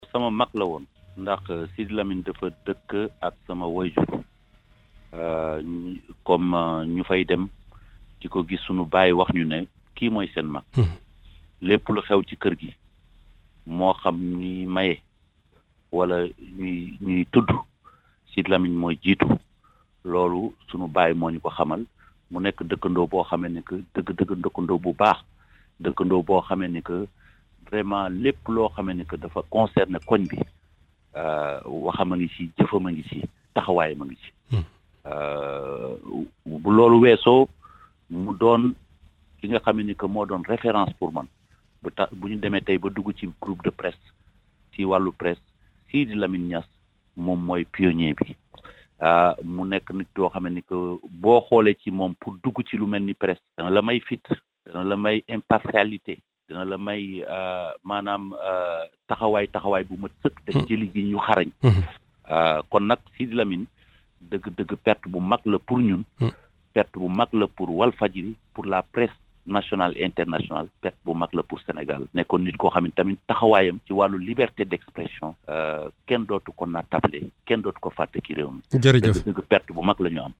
Audio - Ecoutez le témoignage de Youssou Ndour sur Sidy Lamine Niass